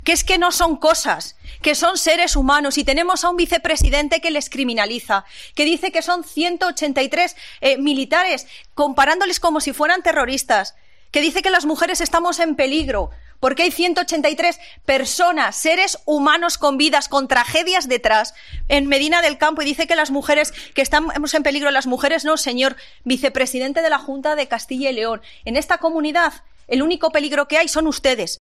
Patricia Gómez (PSOE) acusa al vicepresidente de Castilla y León de criminalizar a los inmigrantes